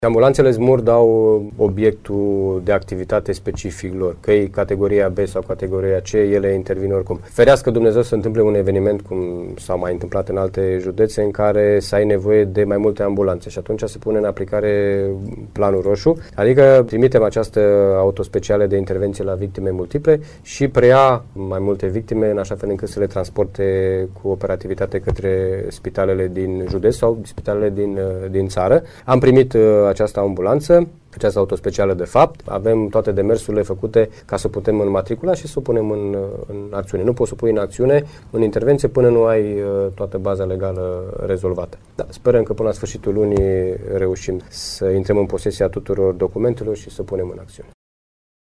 Autovehehiculul va deveni activ când se declanşează „planul roşu”, spune inspectorul şef al ISU, colonel Titus Susan.